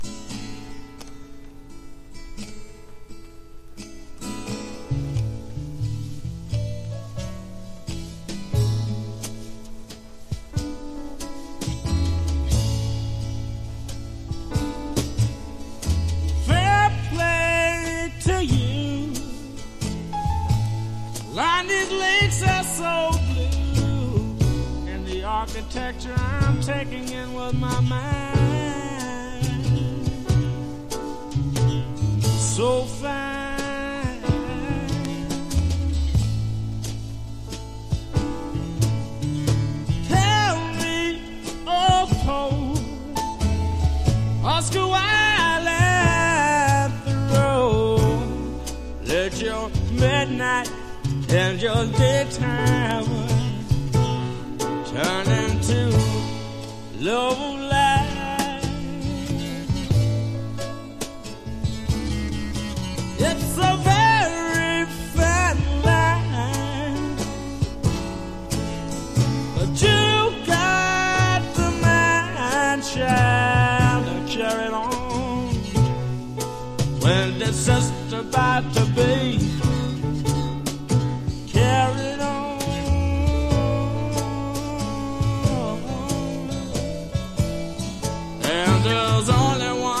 SSW～フォーキー・グルーヴの名作！
SSW / FOLK